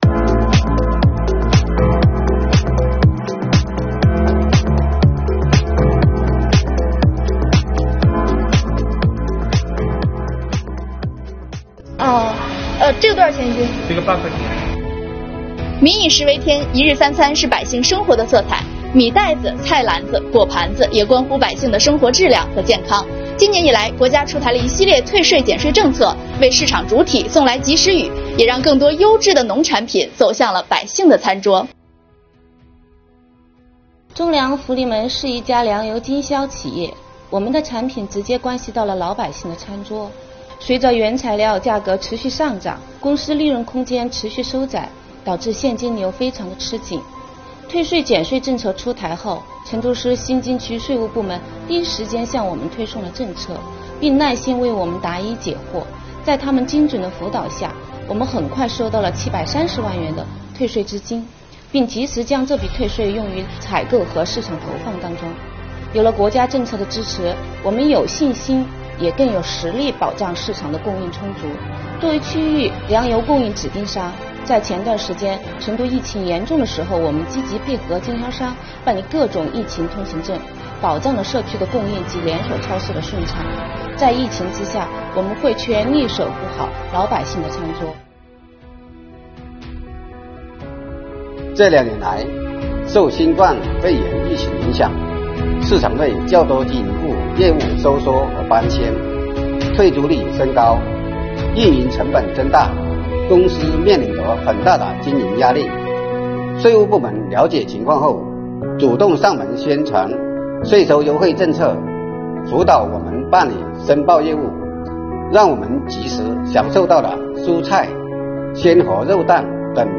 今年以来，国家出台了一系列退税减税政策，为市场主体送来“及时雨”。今天，中国税务报主播带你逛一逛菜市场，感受生活气息，看退税减税政策如何惠及市场主体，助力稳物价、保供应，让更多优质农产品走上百姓餐桌。